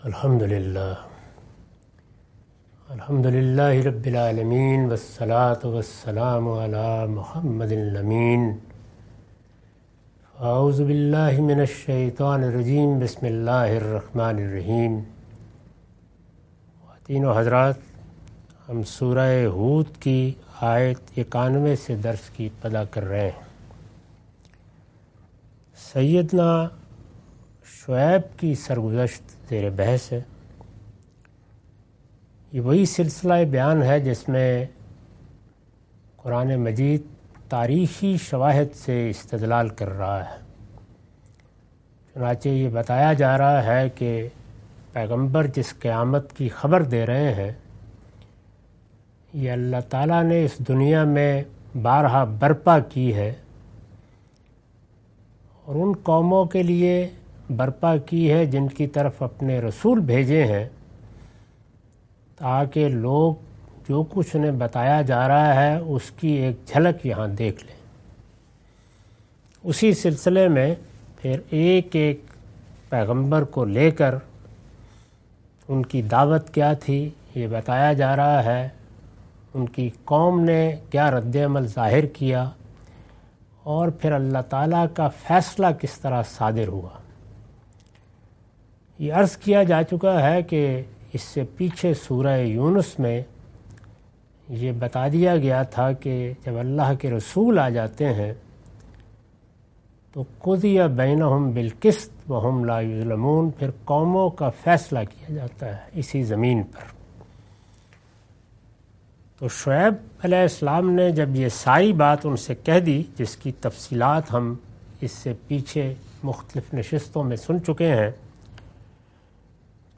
Surah Hud- A lecture of Tafseer-ul-Quran – Al-Bayan by Javed Ahmad Ghamidi. Commentary and explanation of verses 91-97.